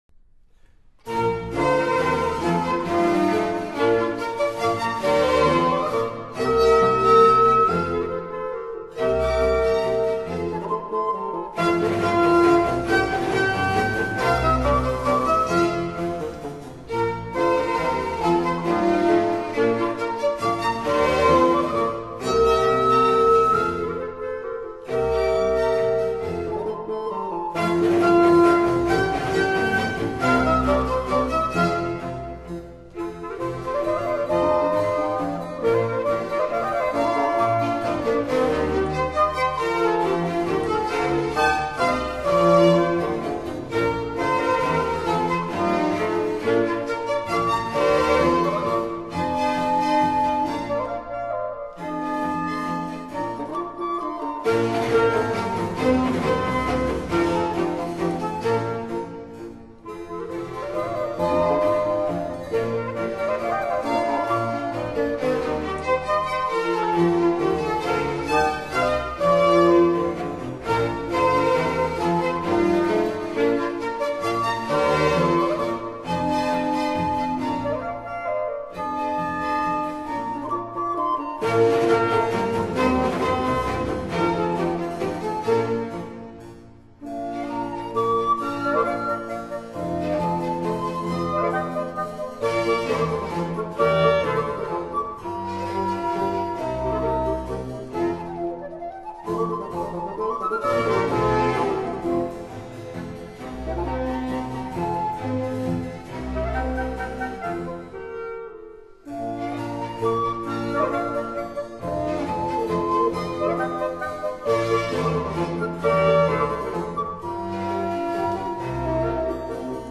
Minuetto - Trio